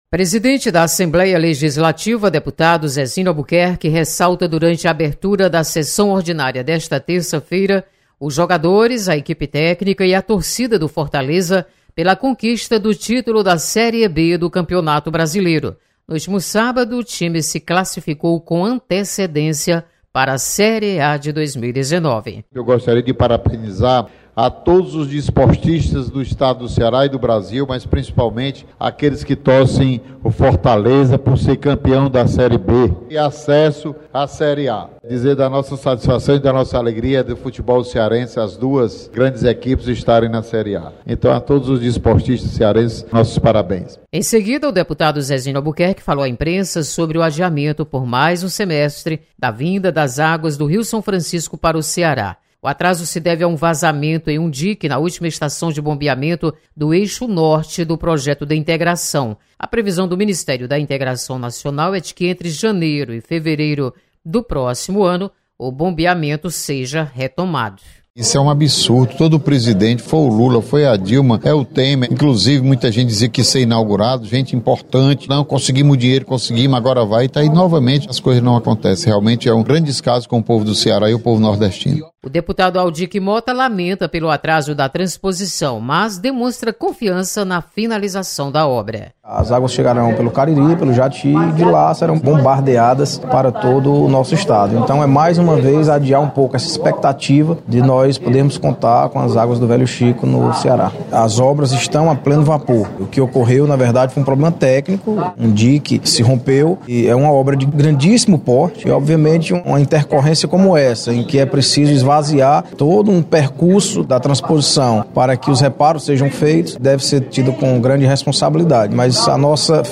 Presidente Zezinho Albuquerque  destaca conquista do título da Série B do Fortaleza  Esporte Clube. Repórter